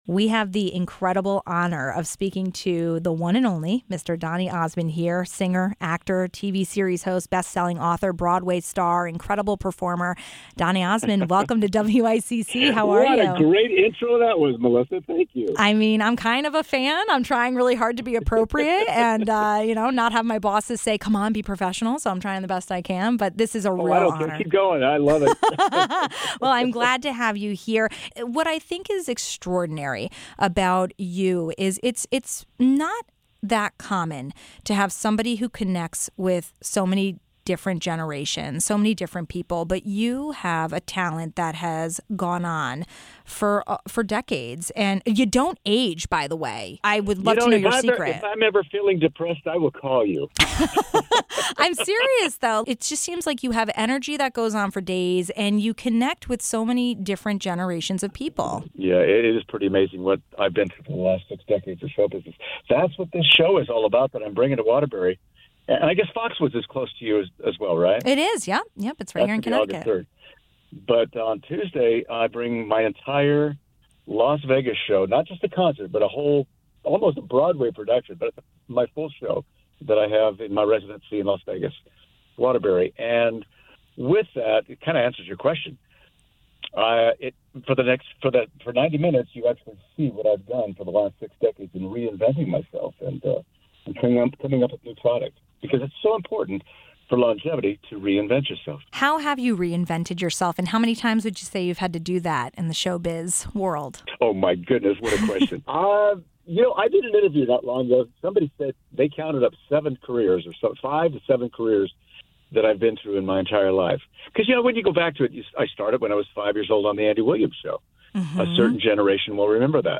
We had the honor of an exclusive interview with legendary artist and performer, Donny Osmond. He spoke with us one week ahead of his national tour, kicking off in Waterbury, Connecticut.